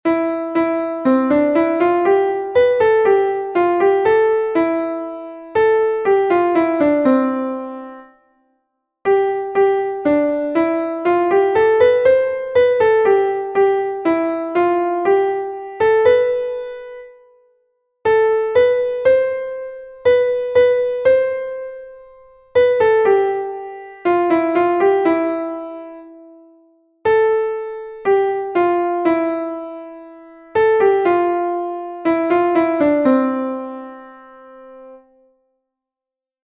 Rhythmic reading 1
Exercise 1. The rhythmic pattern to work with is a dotted crotchet + two semiquavers along with a time signature change (3/4 + 2/4), both in binary subdivision with this equivalence: crotchet = crotchet.